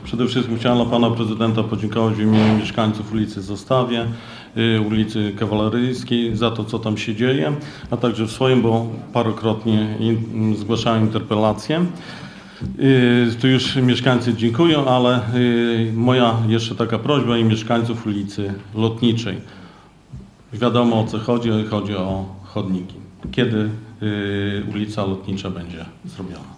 Tak było chociażby na ostatniej sesji rady miejskiej, kiedy to Józef Murawko z Bloku Samorządowego prosił o remont chodników na ulicy Lotniczej.